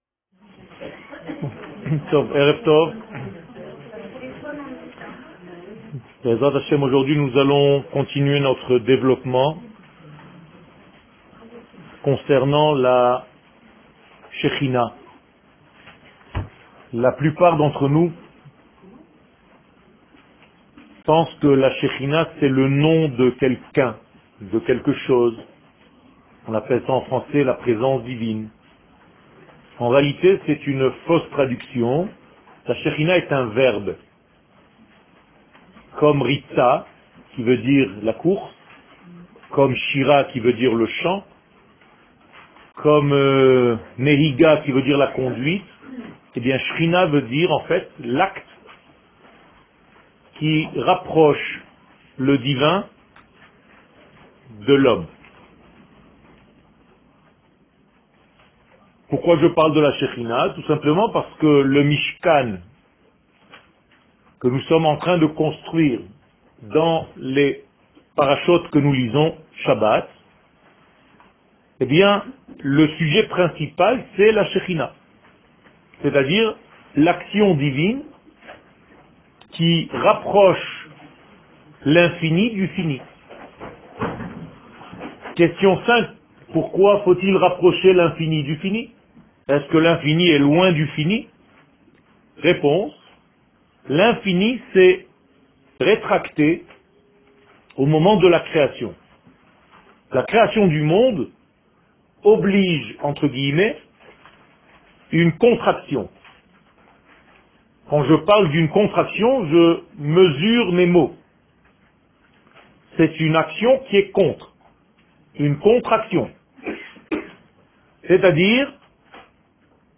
Le Temple de Moshe et le Temple d'Aaron Eretz Israel שיעור מ 21 פברואר 2016 01H 01MIN הורדה בקובץ אודיו MP3 (10.5 Mo) הורדה בקובץ אודיו M4A (7.28 Mo) TAGS : Parasha Torah et identite d'Israel שיעורים קצרים